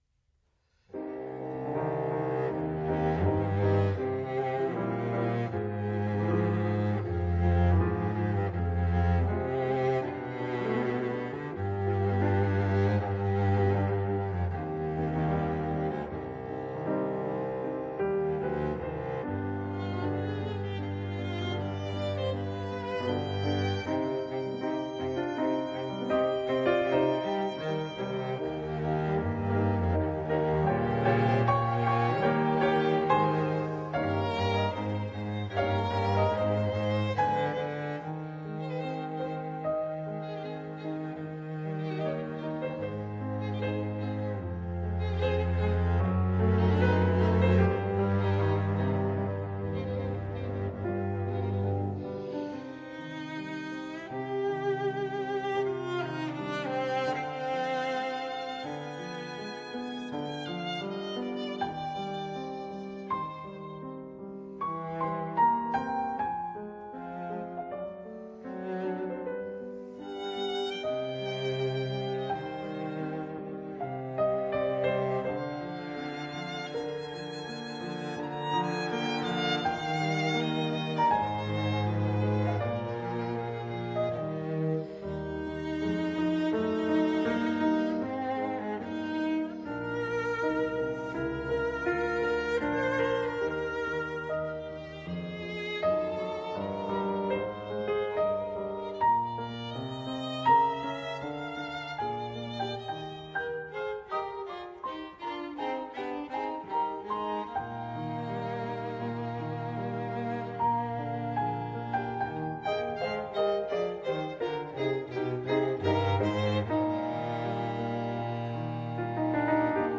Soundbite 2nd Movt
The second movement, Adagio sostenuto, with a sad melody in the cello's lower registered. A melancholy air hangs over the music of the sort one might feel upon entering Grandmother's apartment just after her funeral.
farrenc-pno-trio1-movt2.mp3